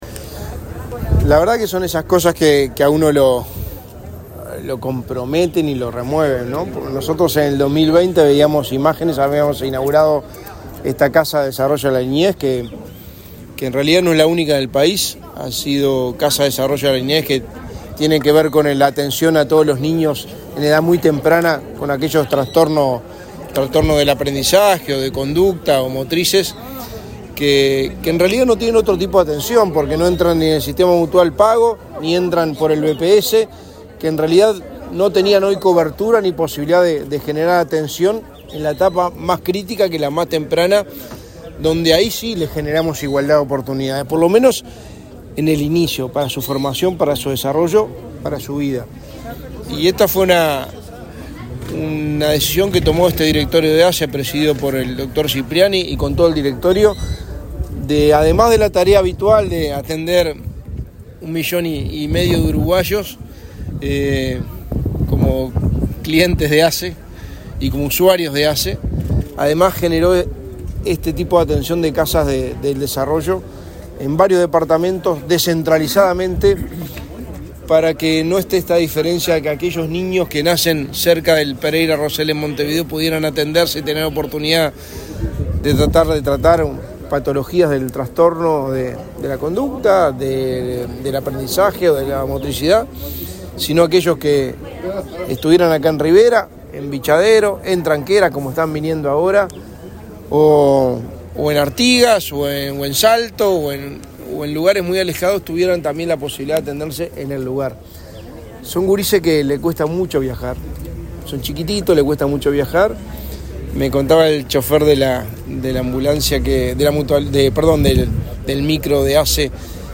Declaraciones del secretario de Presidencia, Álvaro Delgado
Declaraciones del secretario de Presidencia, Álvaro Delgado 01/11/2023 Compartir Facebook X Copiar enlace WhatsApp LinkedIn Este miércoles 1.°, el secretario de Presidencia de la República, Álvaro Delgado, dialogó con la prensa en Rivera, durante una visita a las nuevas instalaciones de la Casa del Desarrollo de la Niñez.